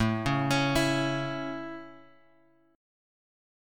A chord {5 4 x 6 5 x} chord
A-Major-A-5,4,x,6,5,x-8.m4a